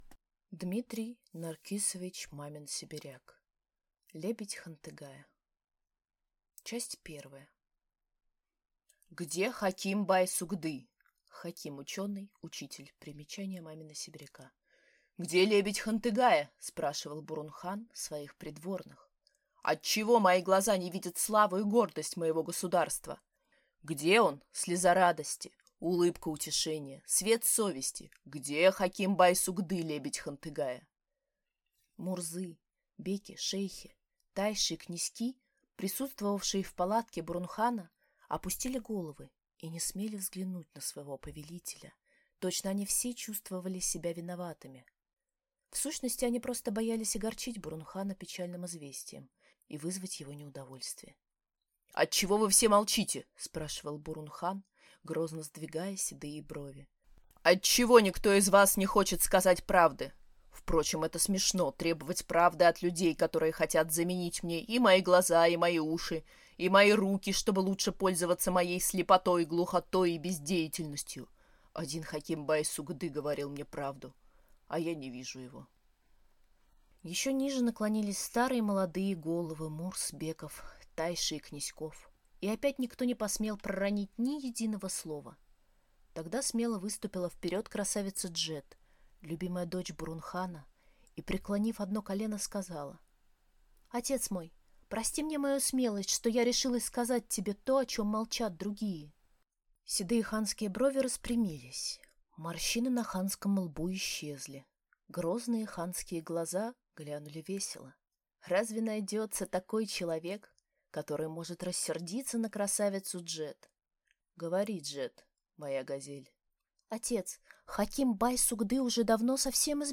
Аудиокнига Лебедь Хантыгая | Библиотека аудиокниг